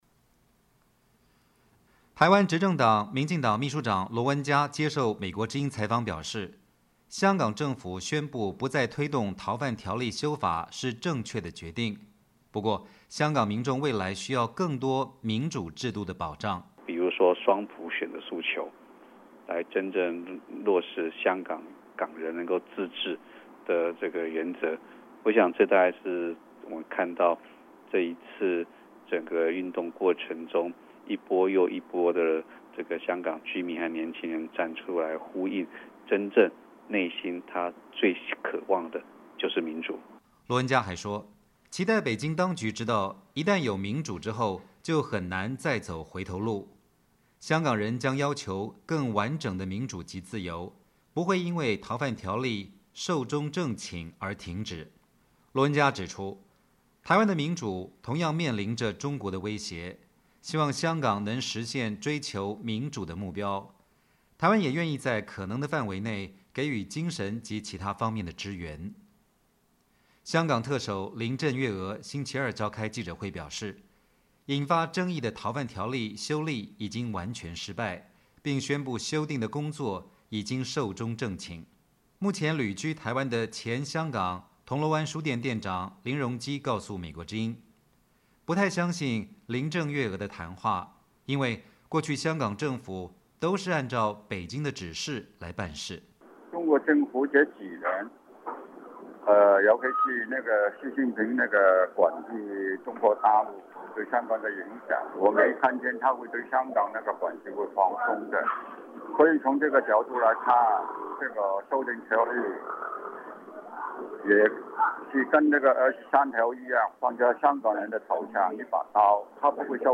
台湾执政党民进党秘书长罗文嘉接受美国之音采访表示，香港政府宣布不再推动逃犯条例修法是正确的决定，不过，香港民众未来需要更多民主制度的保障。